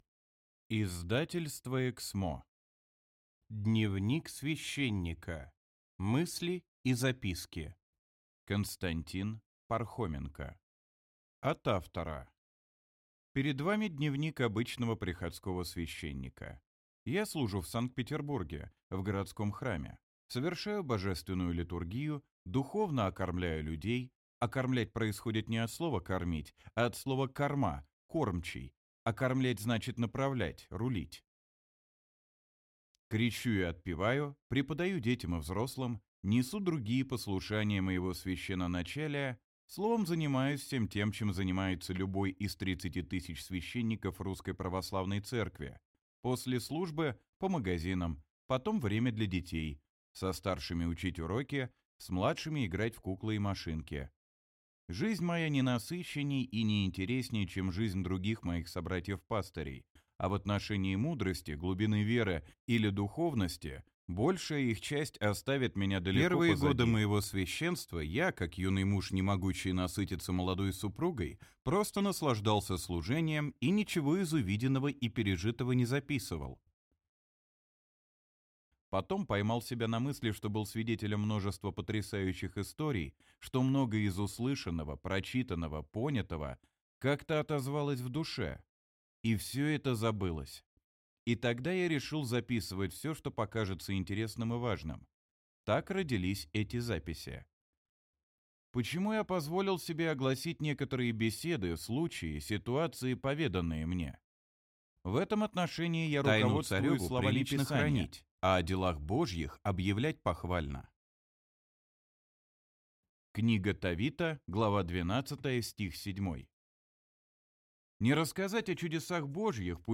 Аудиокнига Дневник священника. Мысли и записки | Библиотека аудиокниг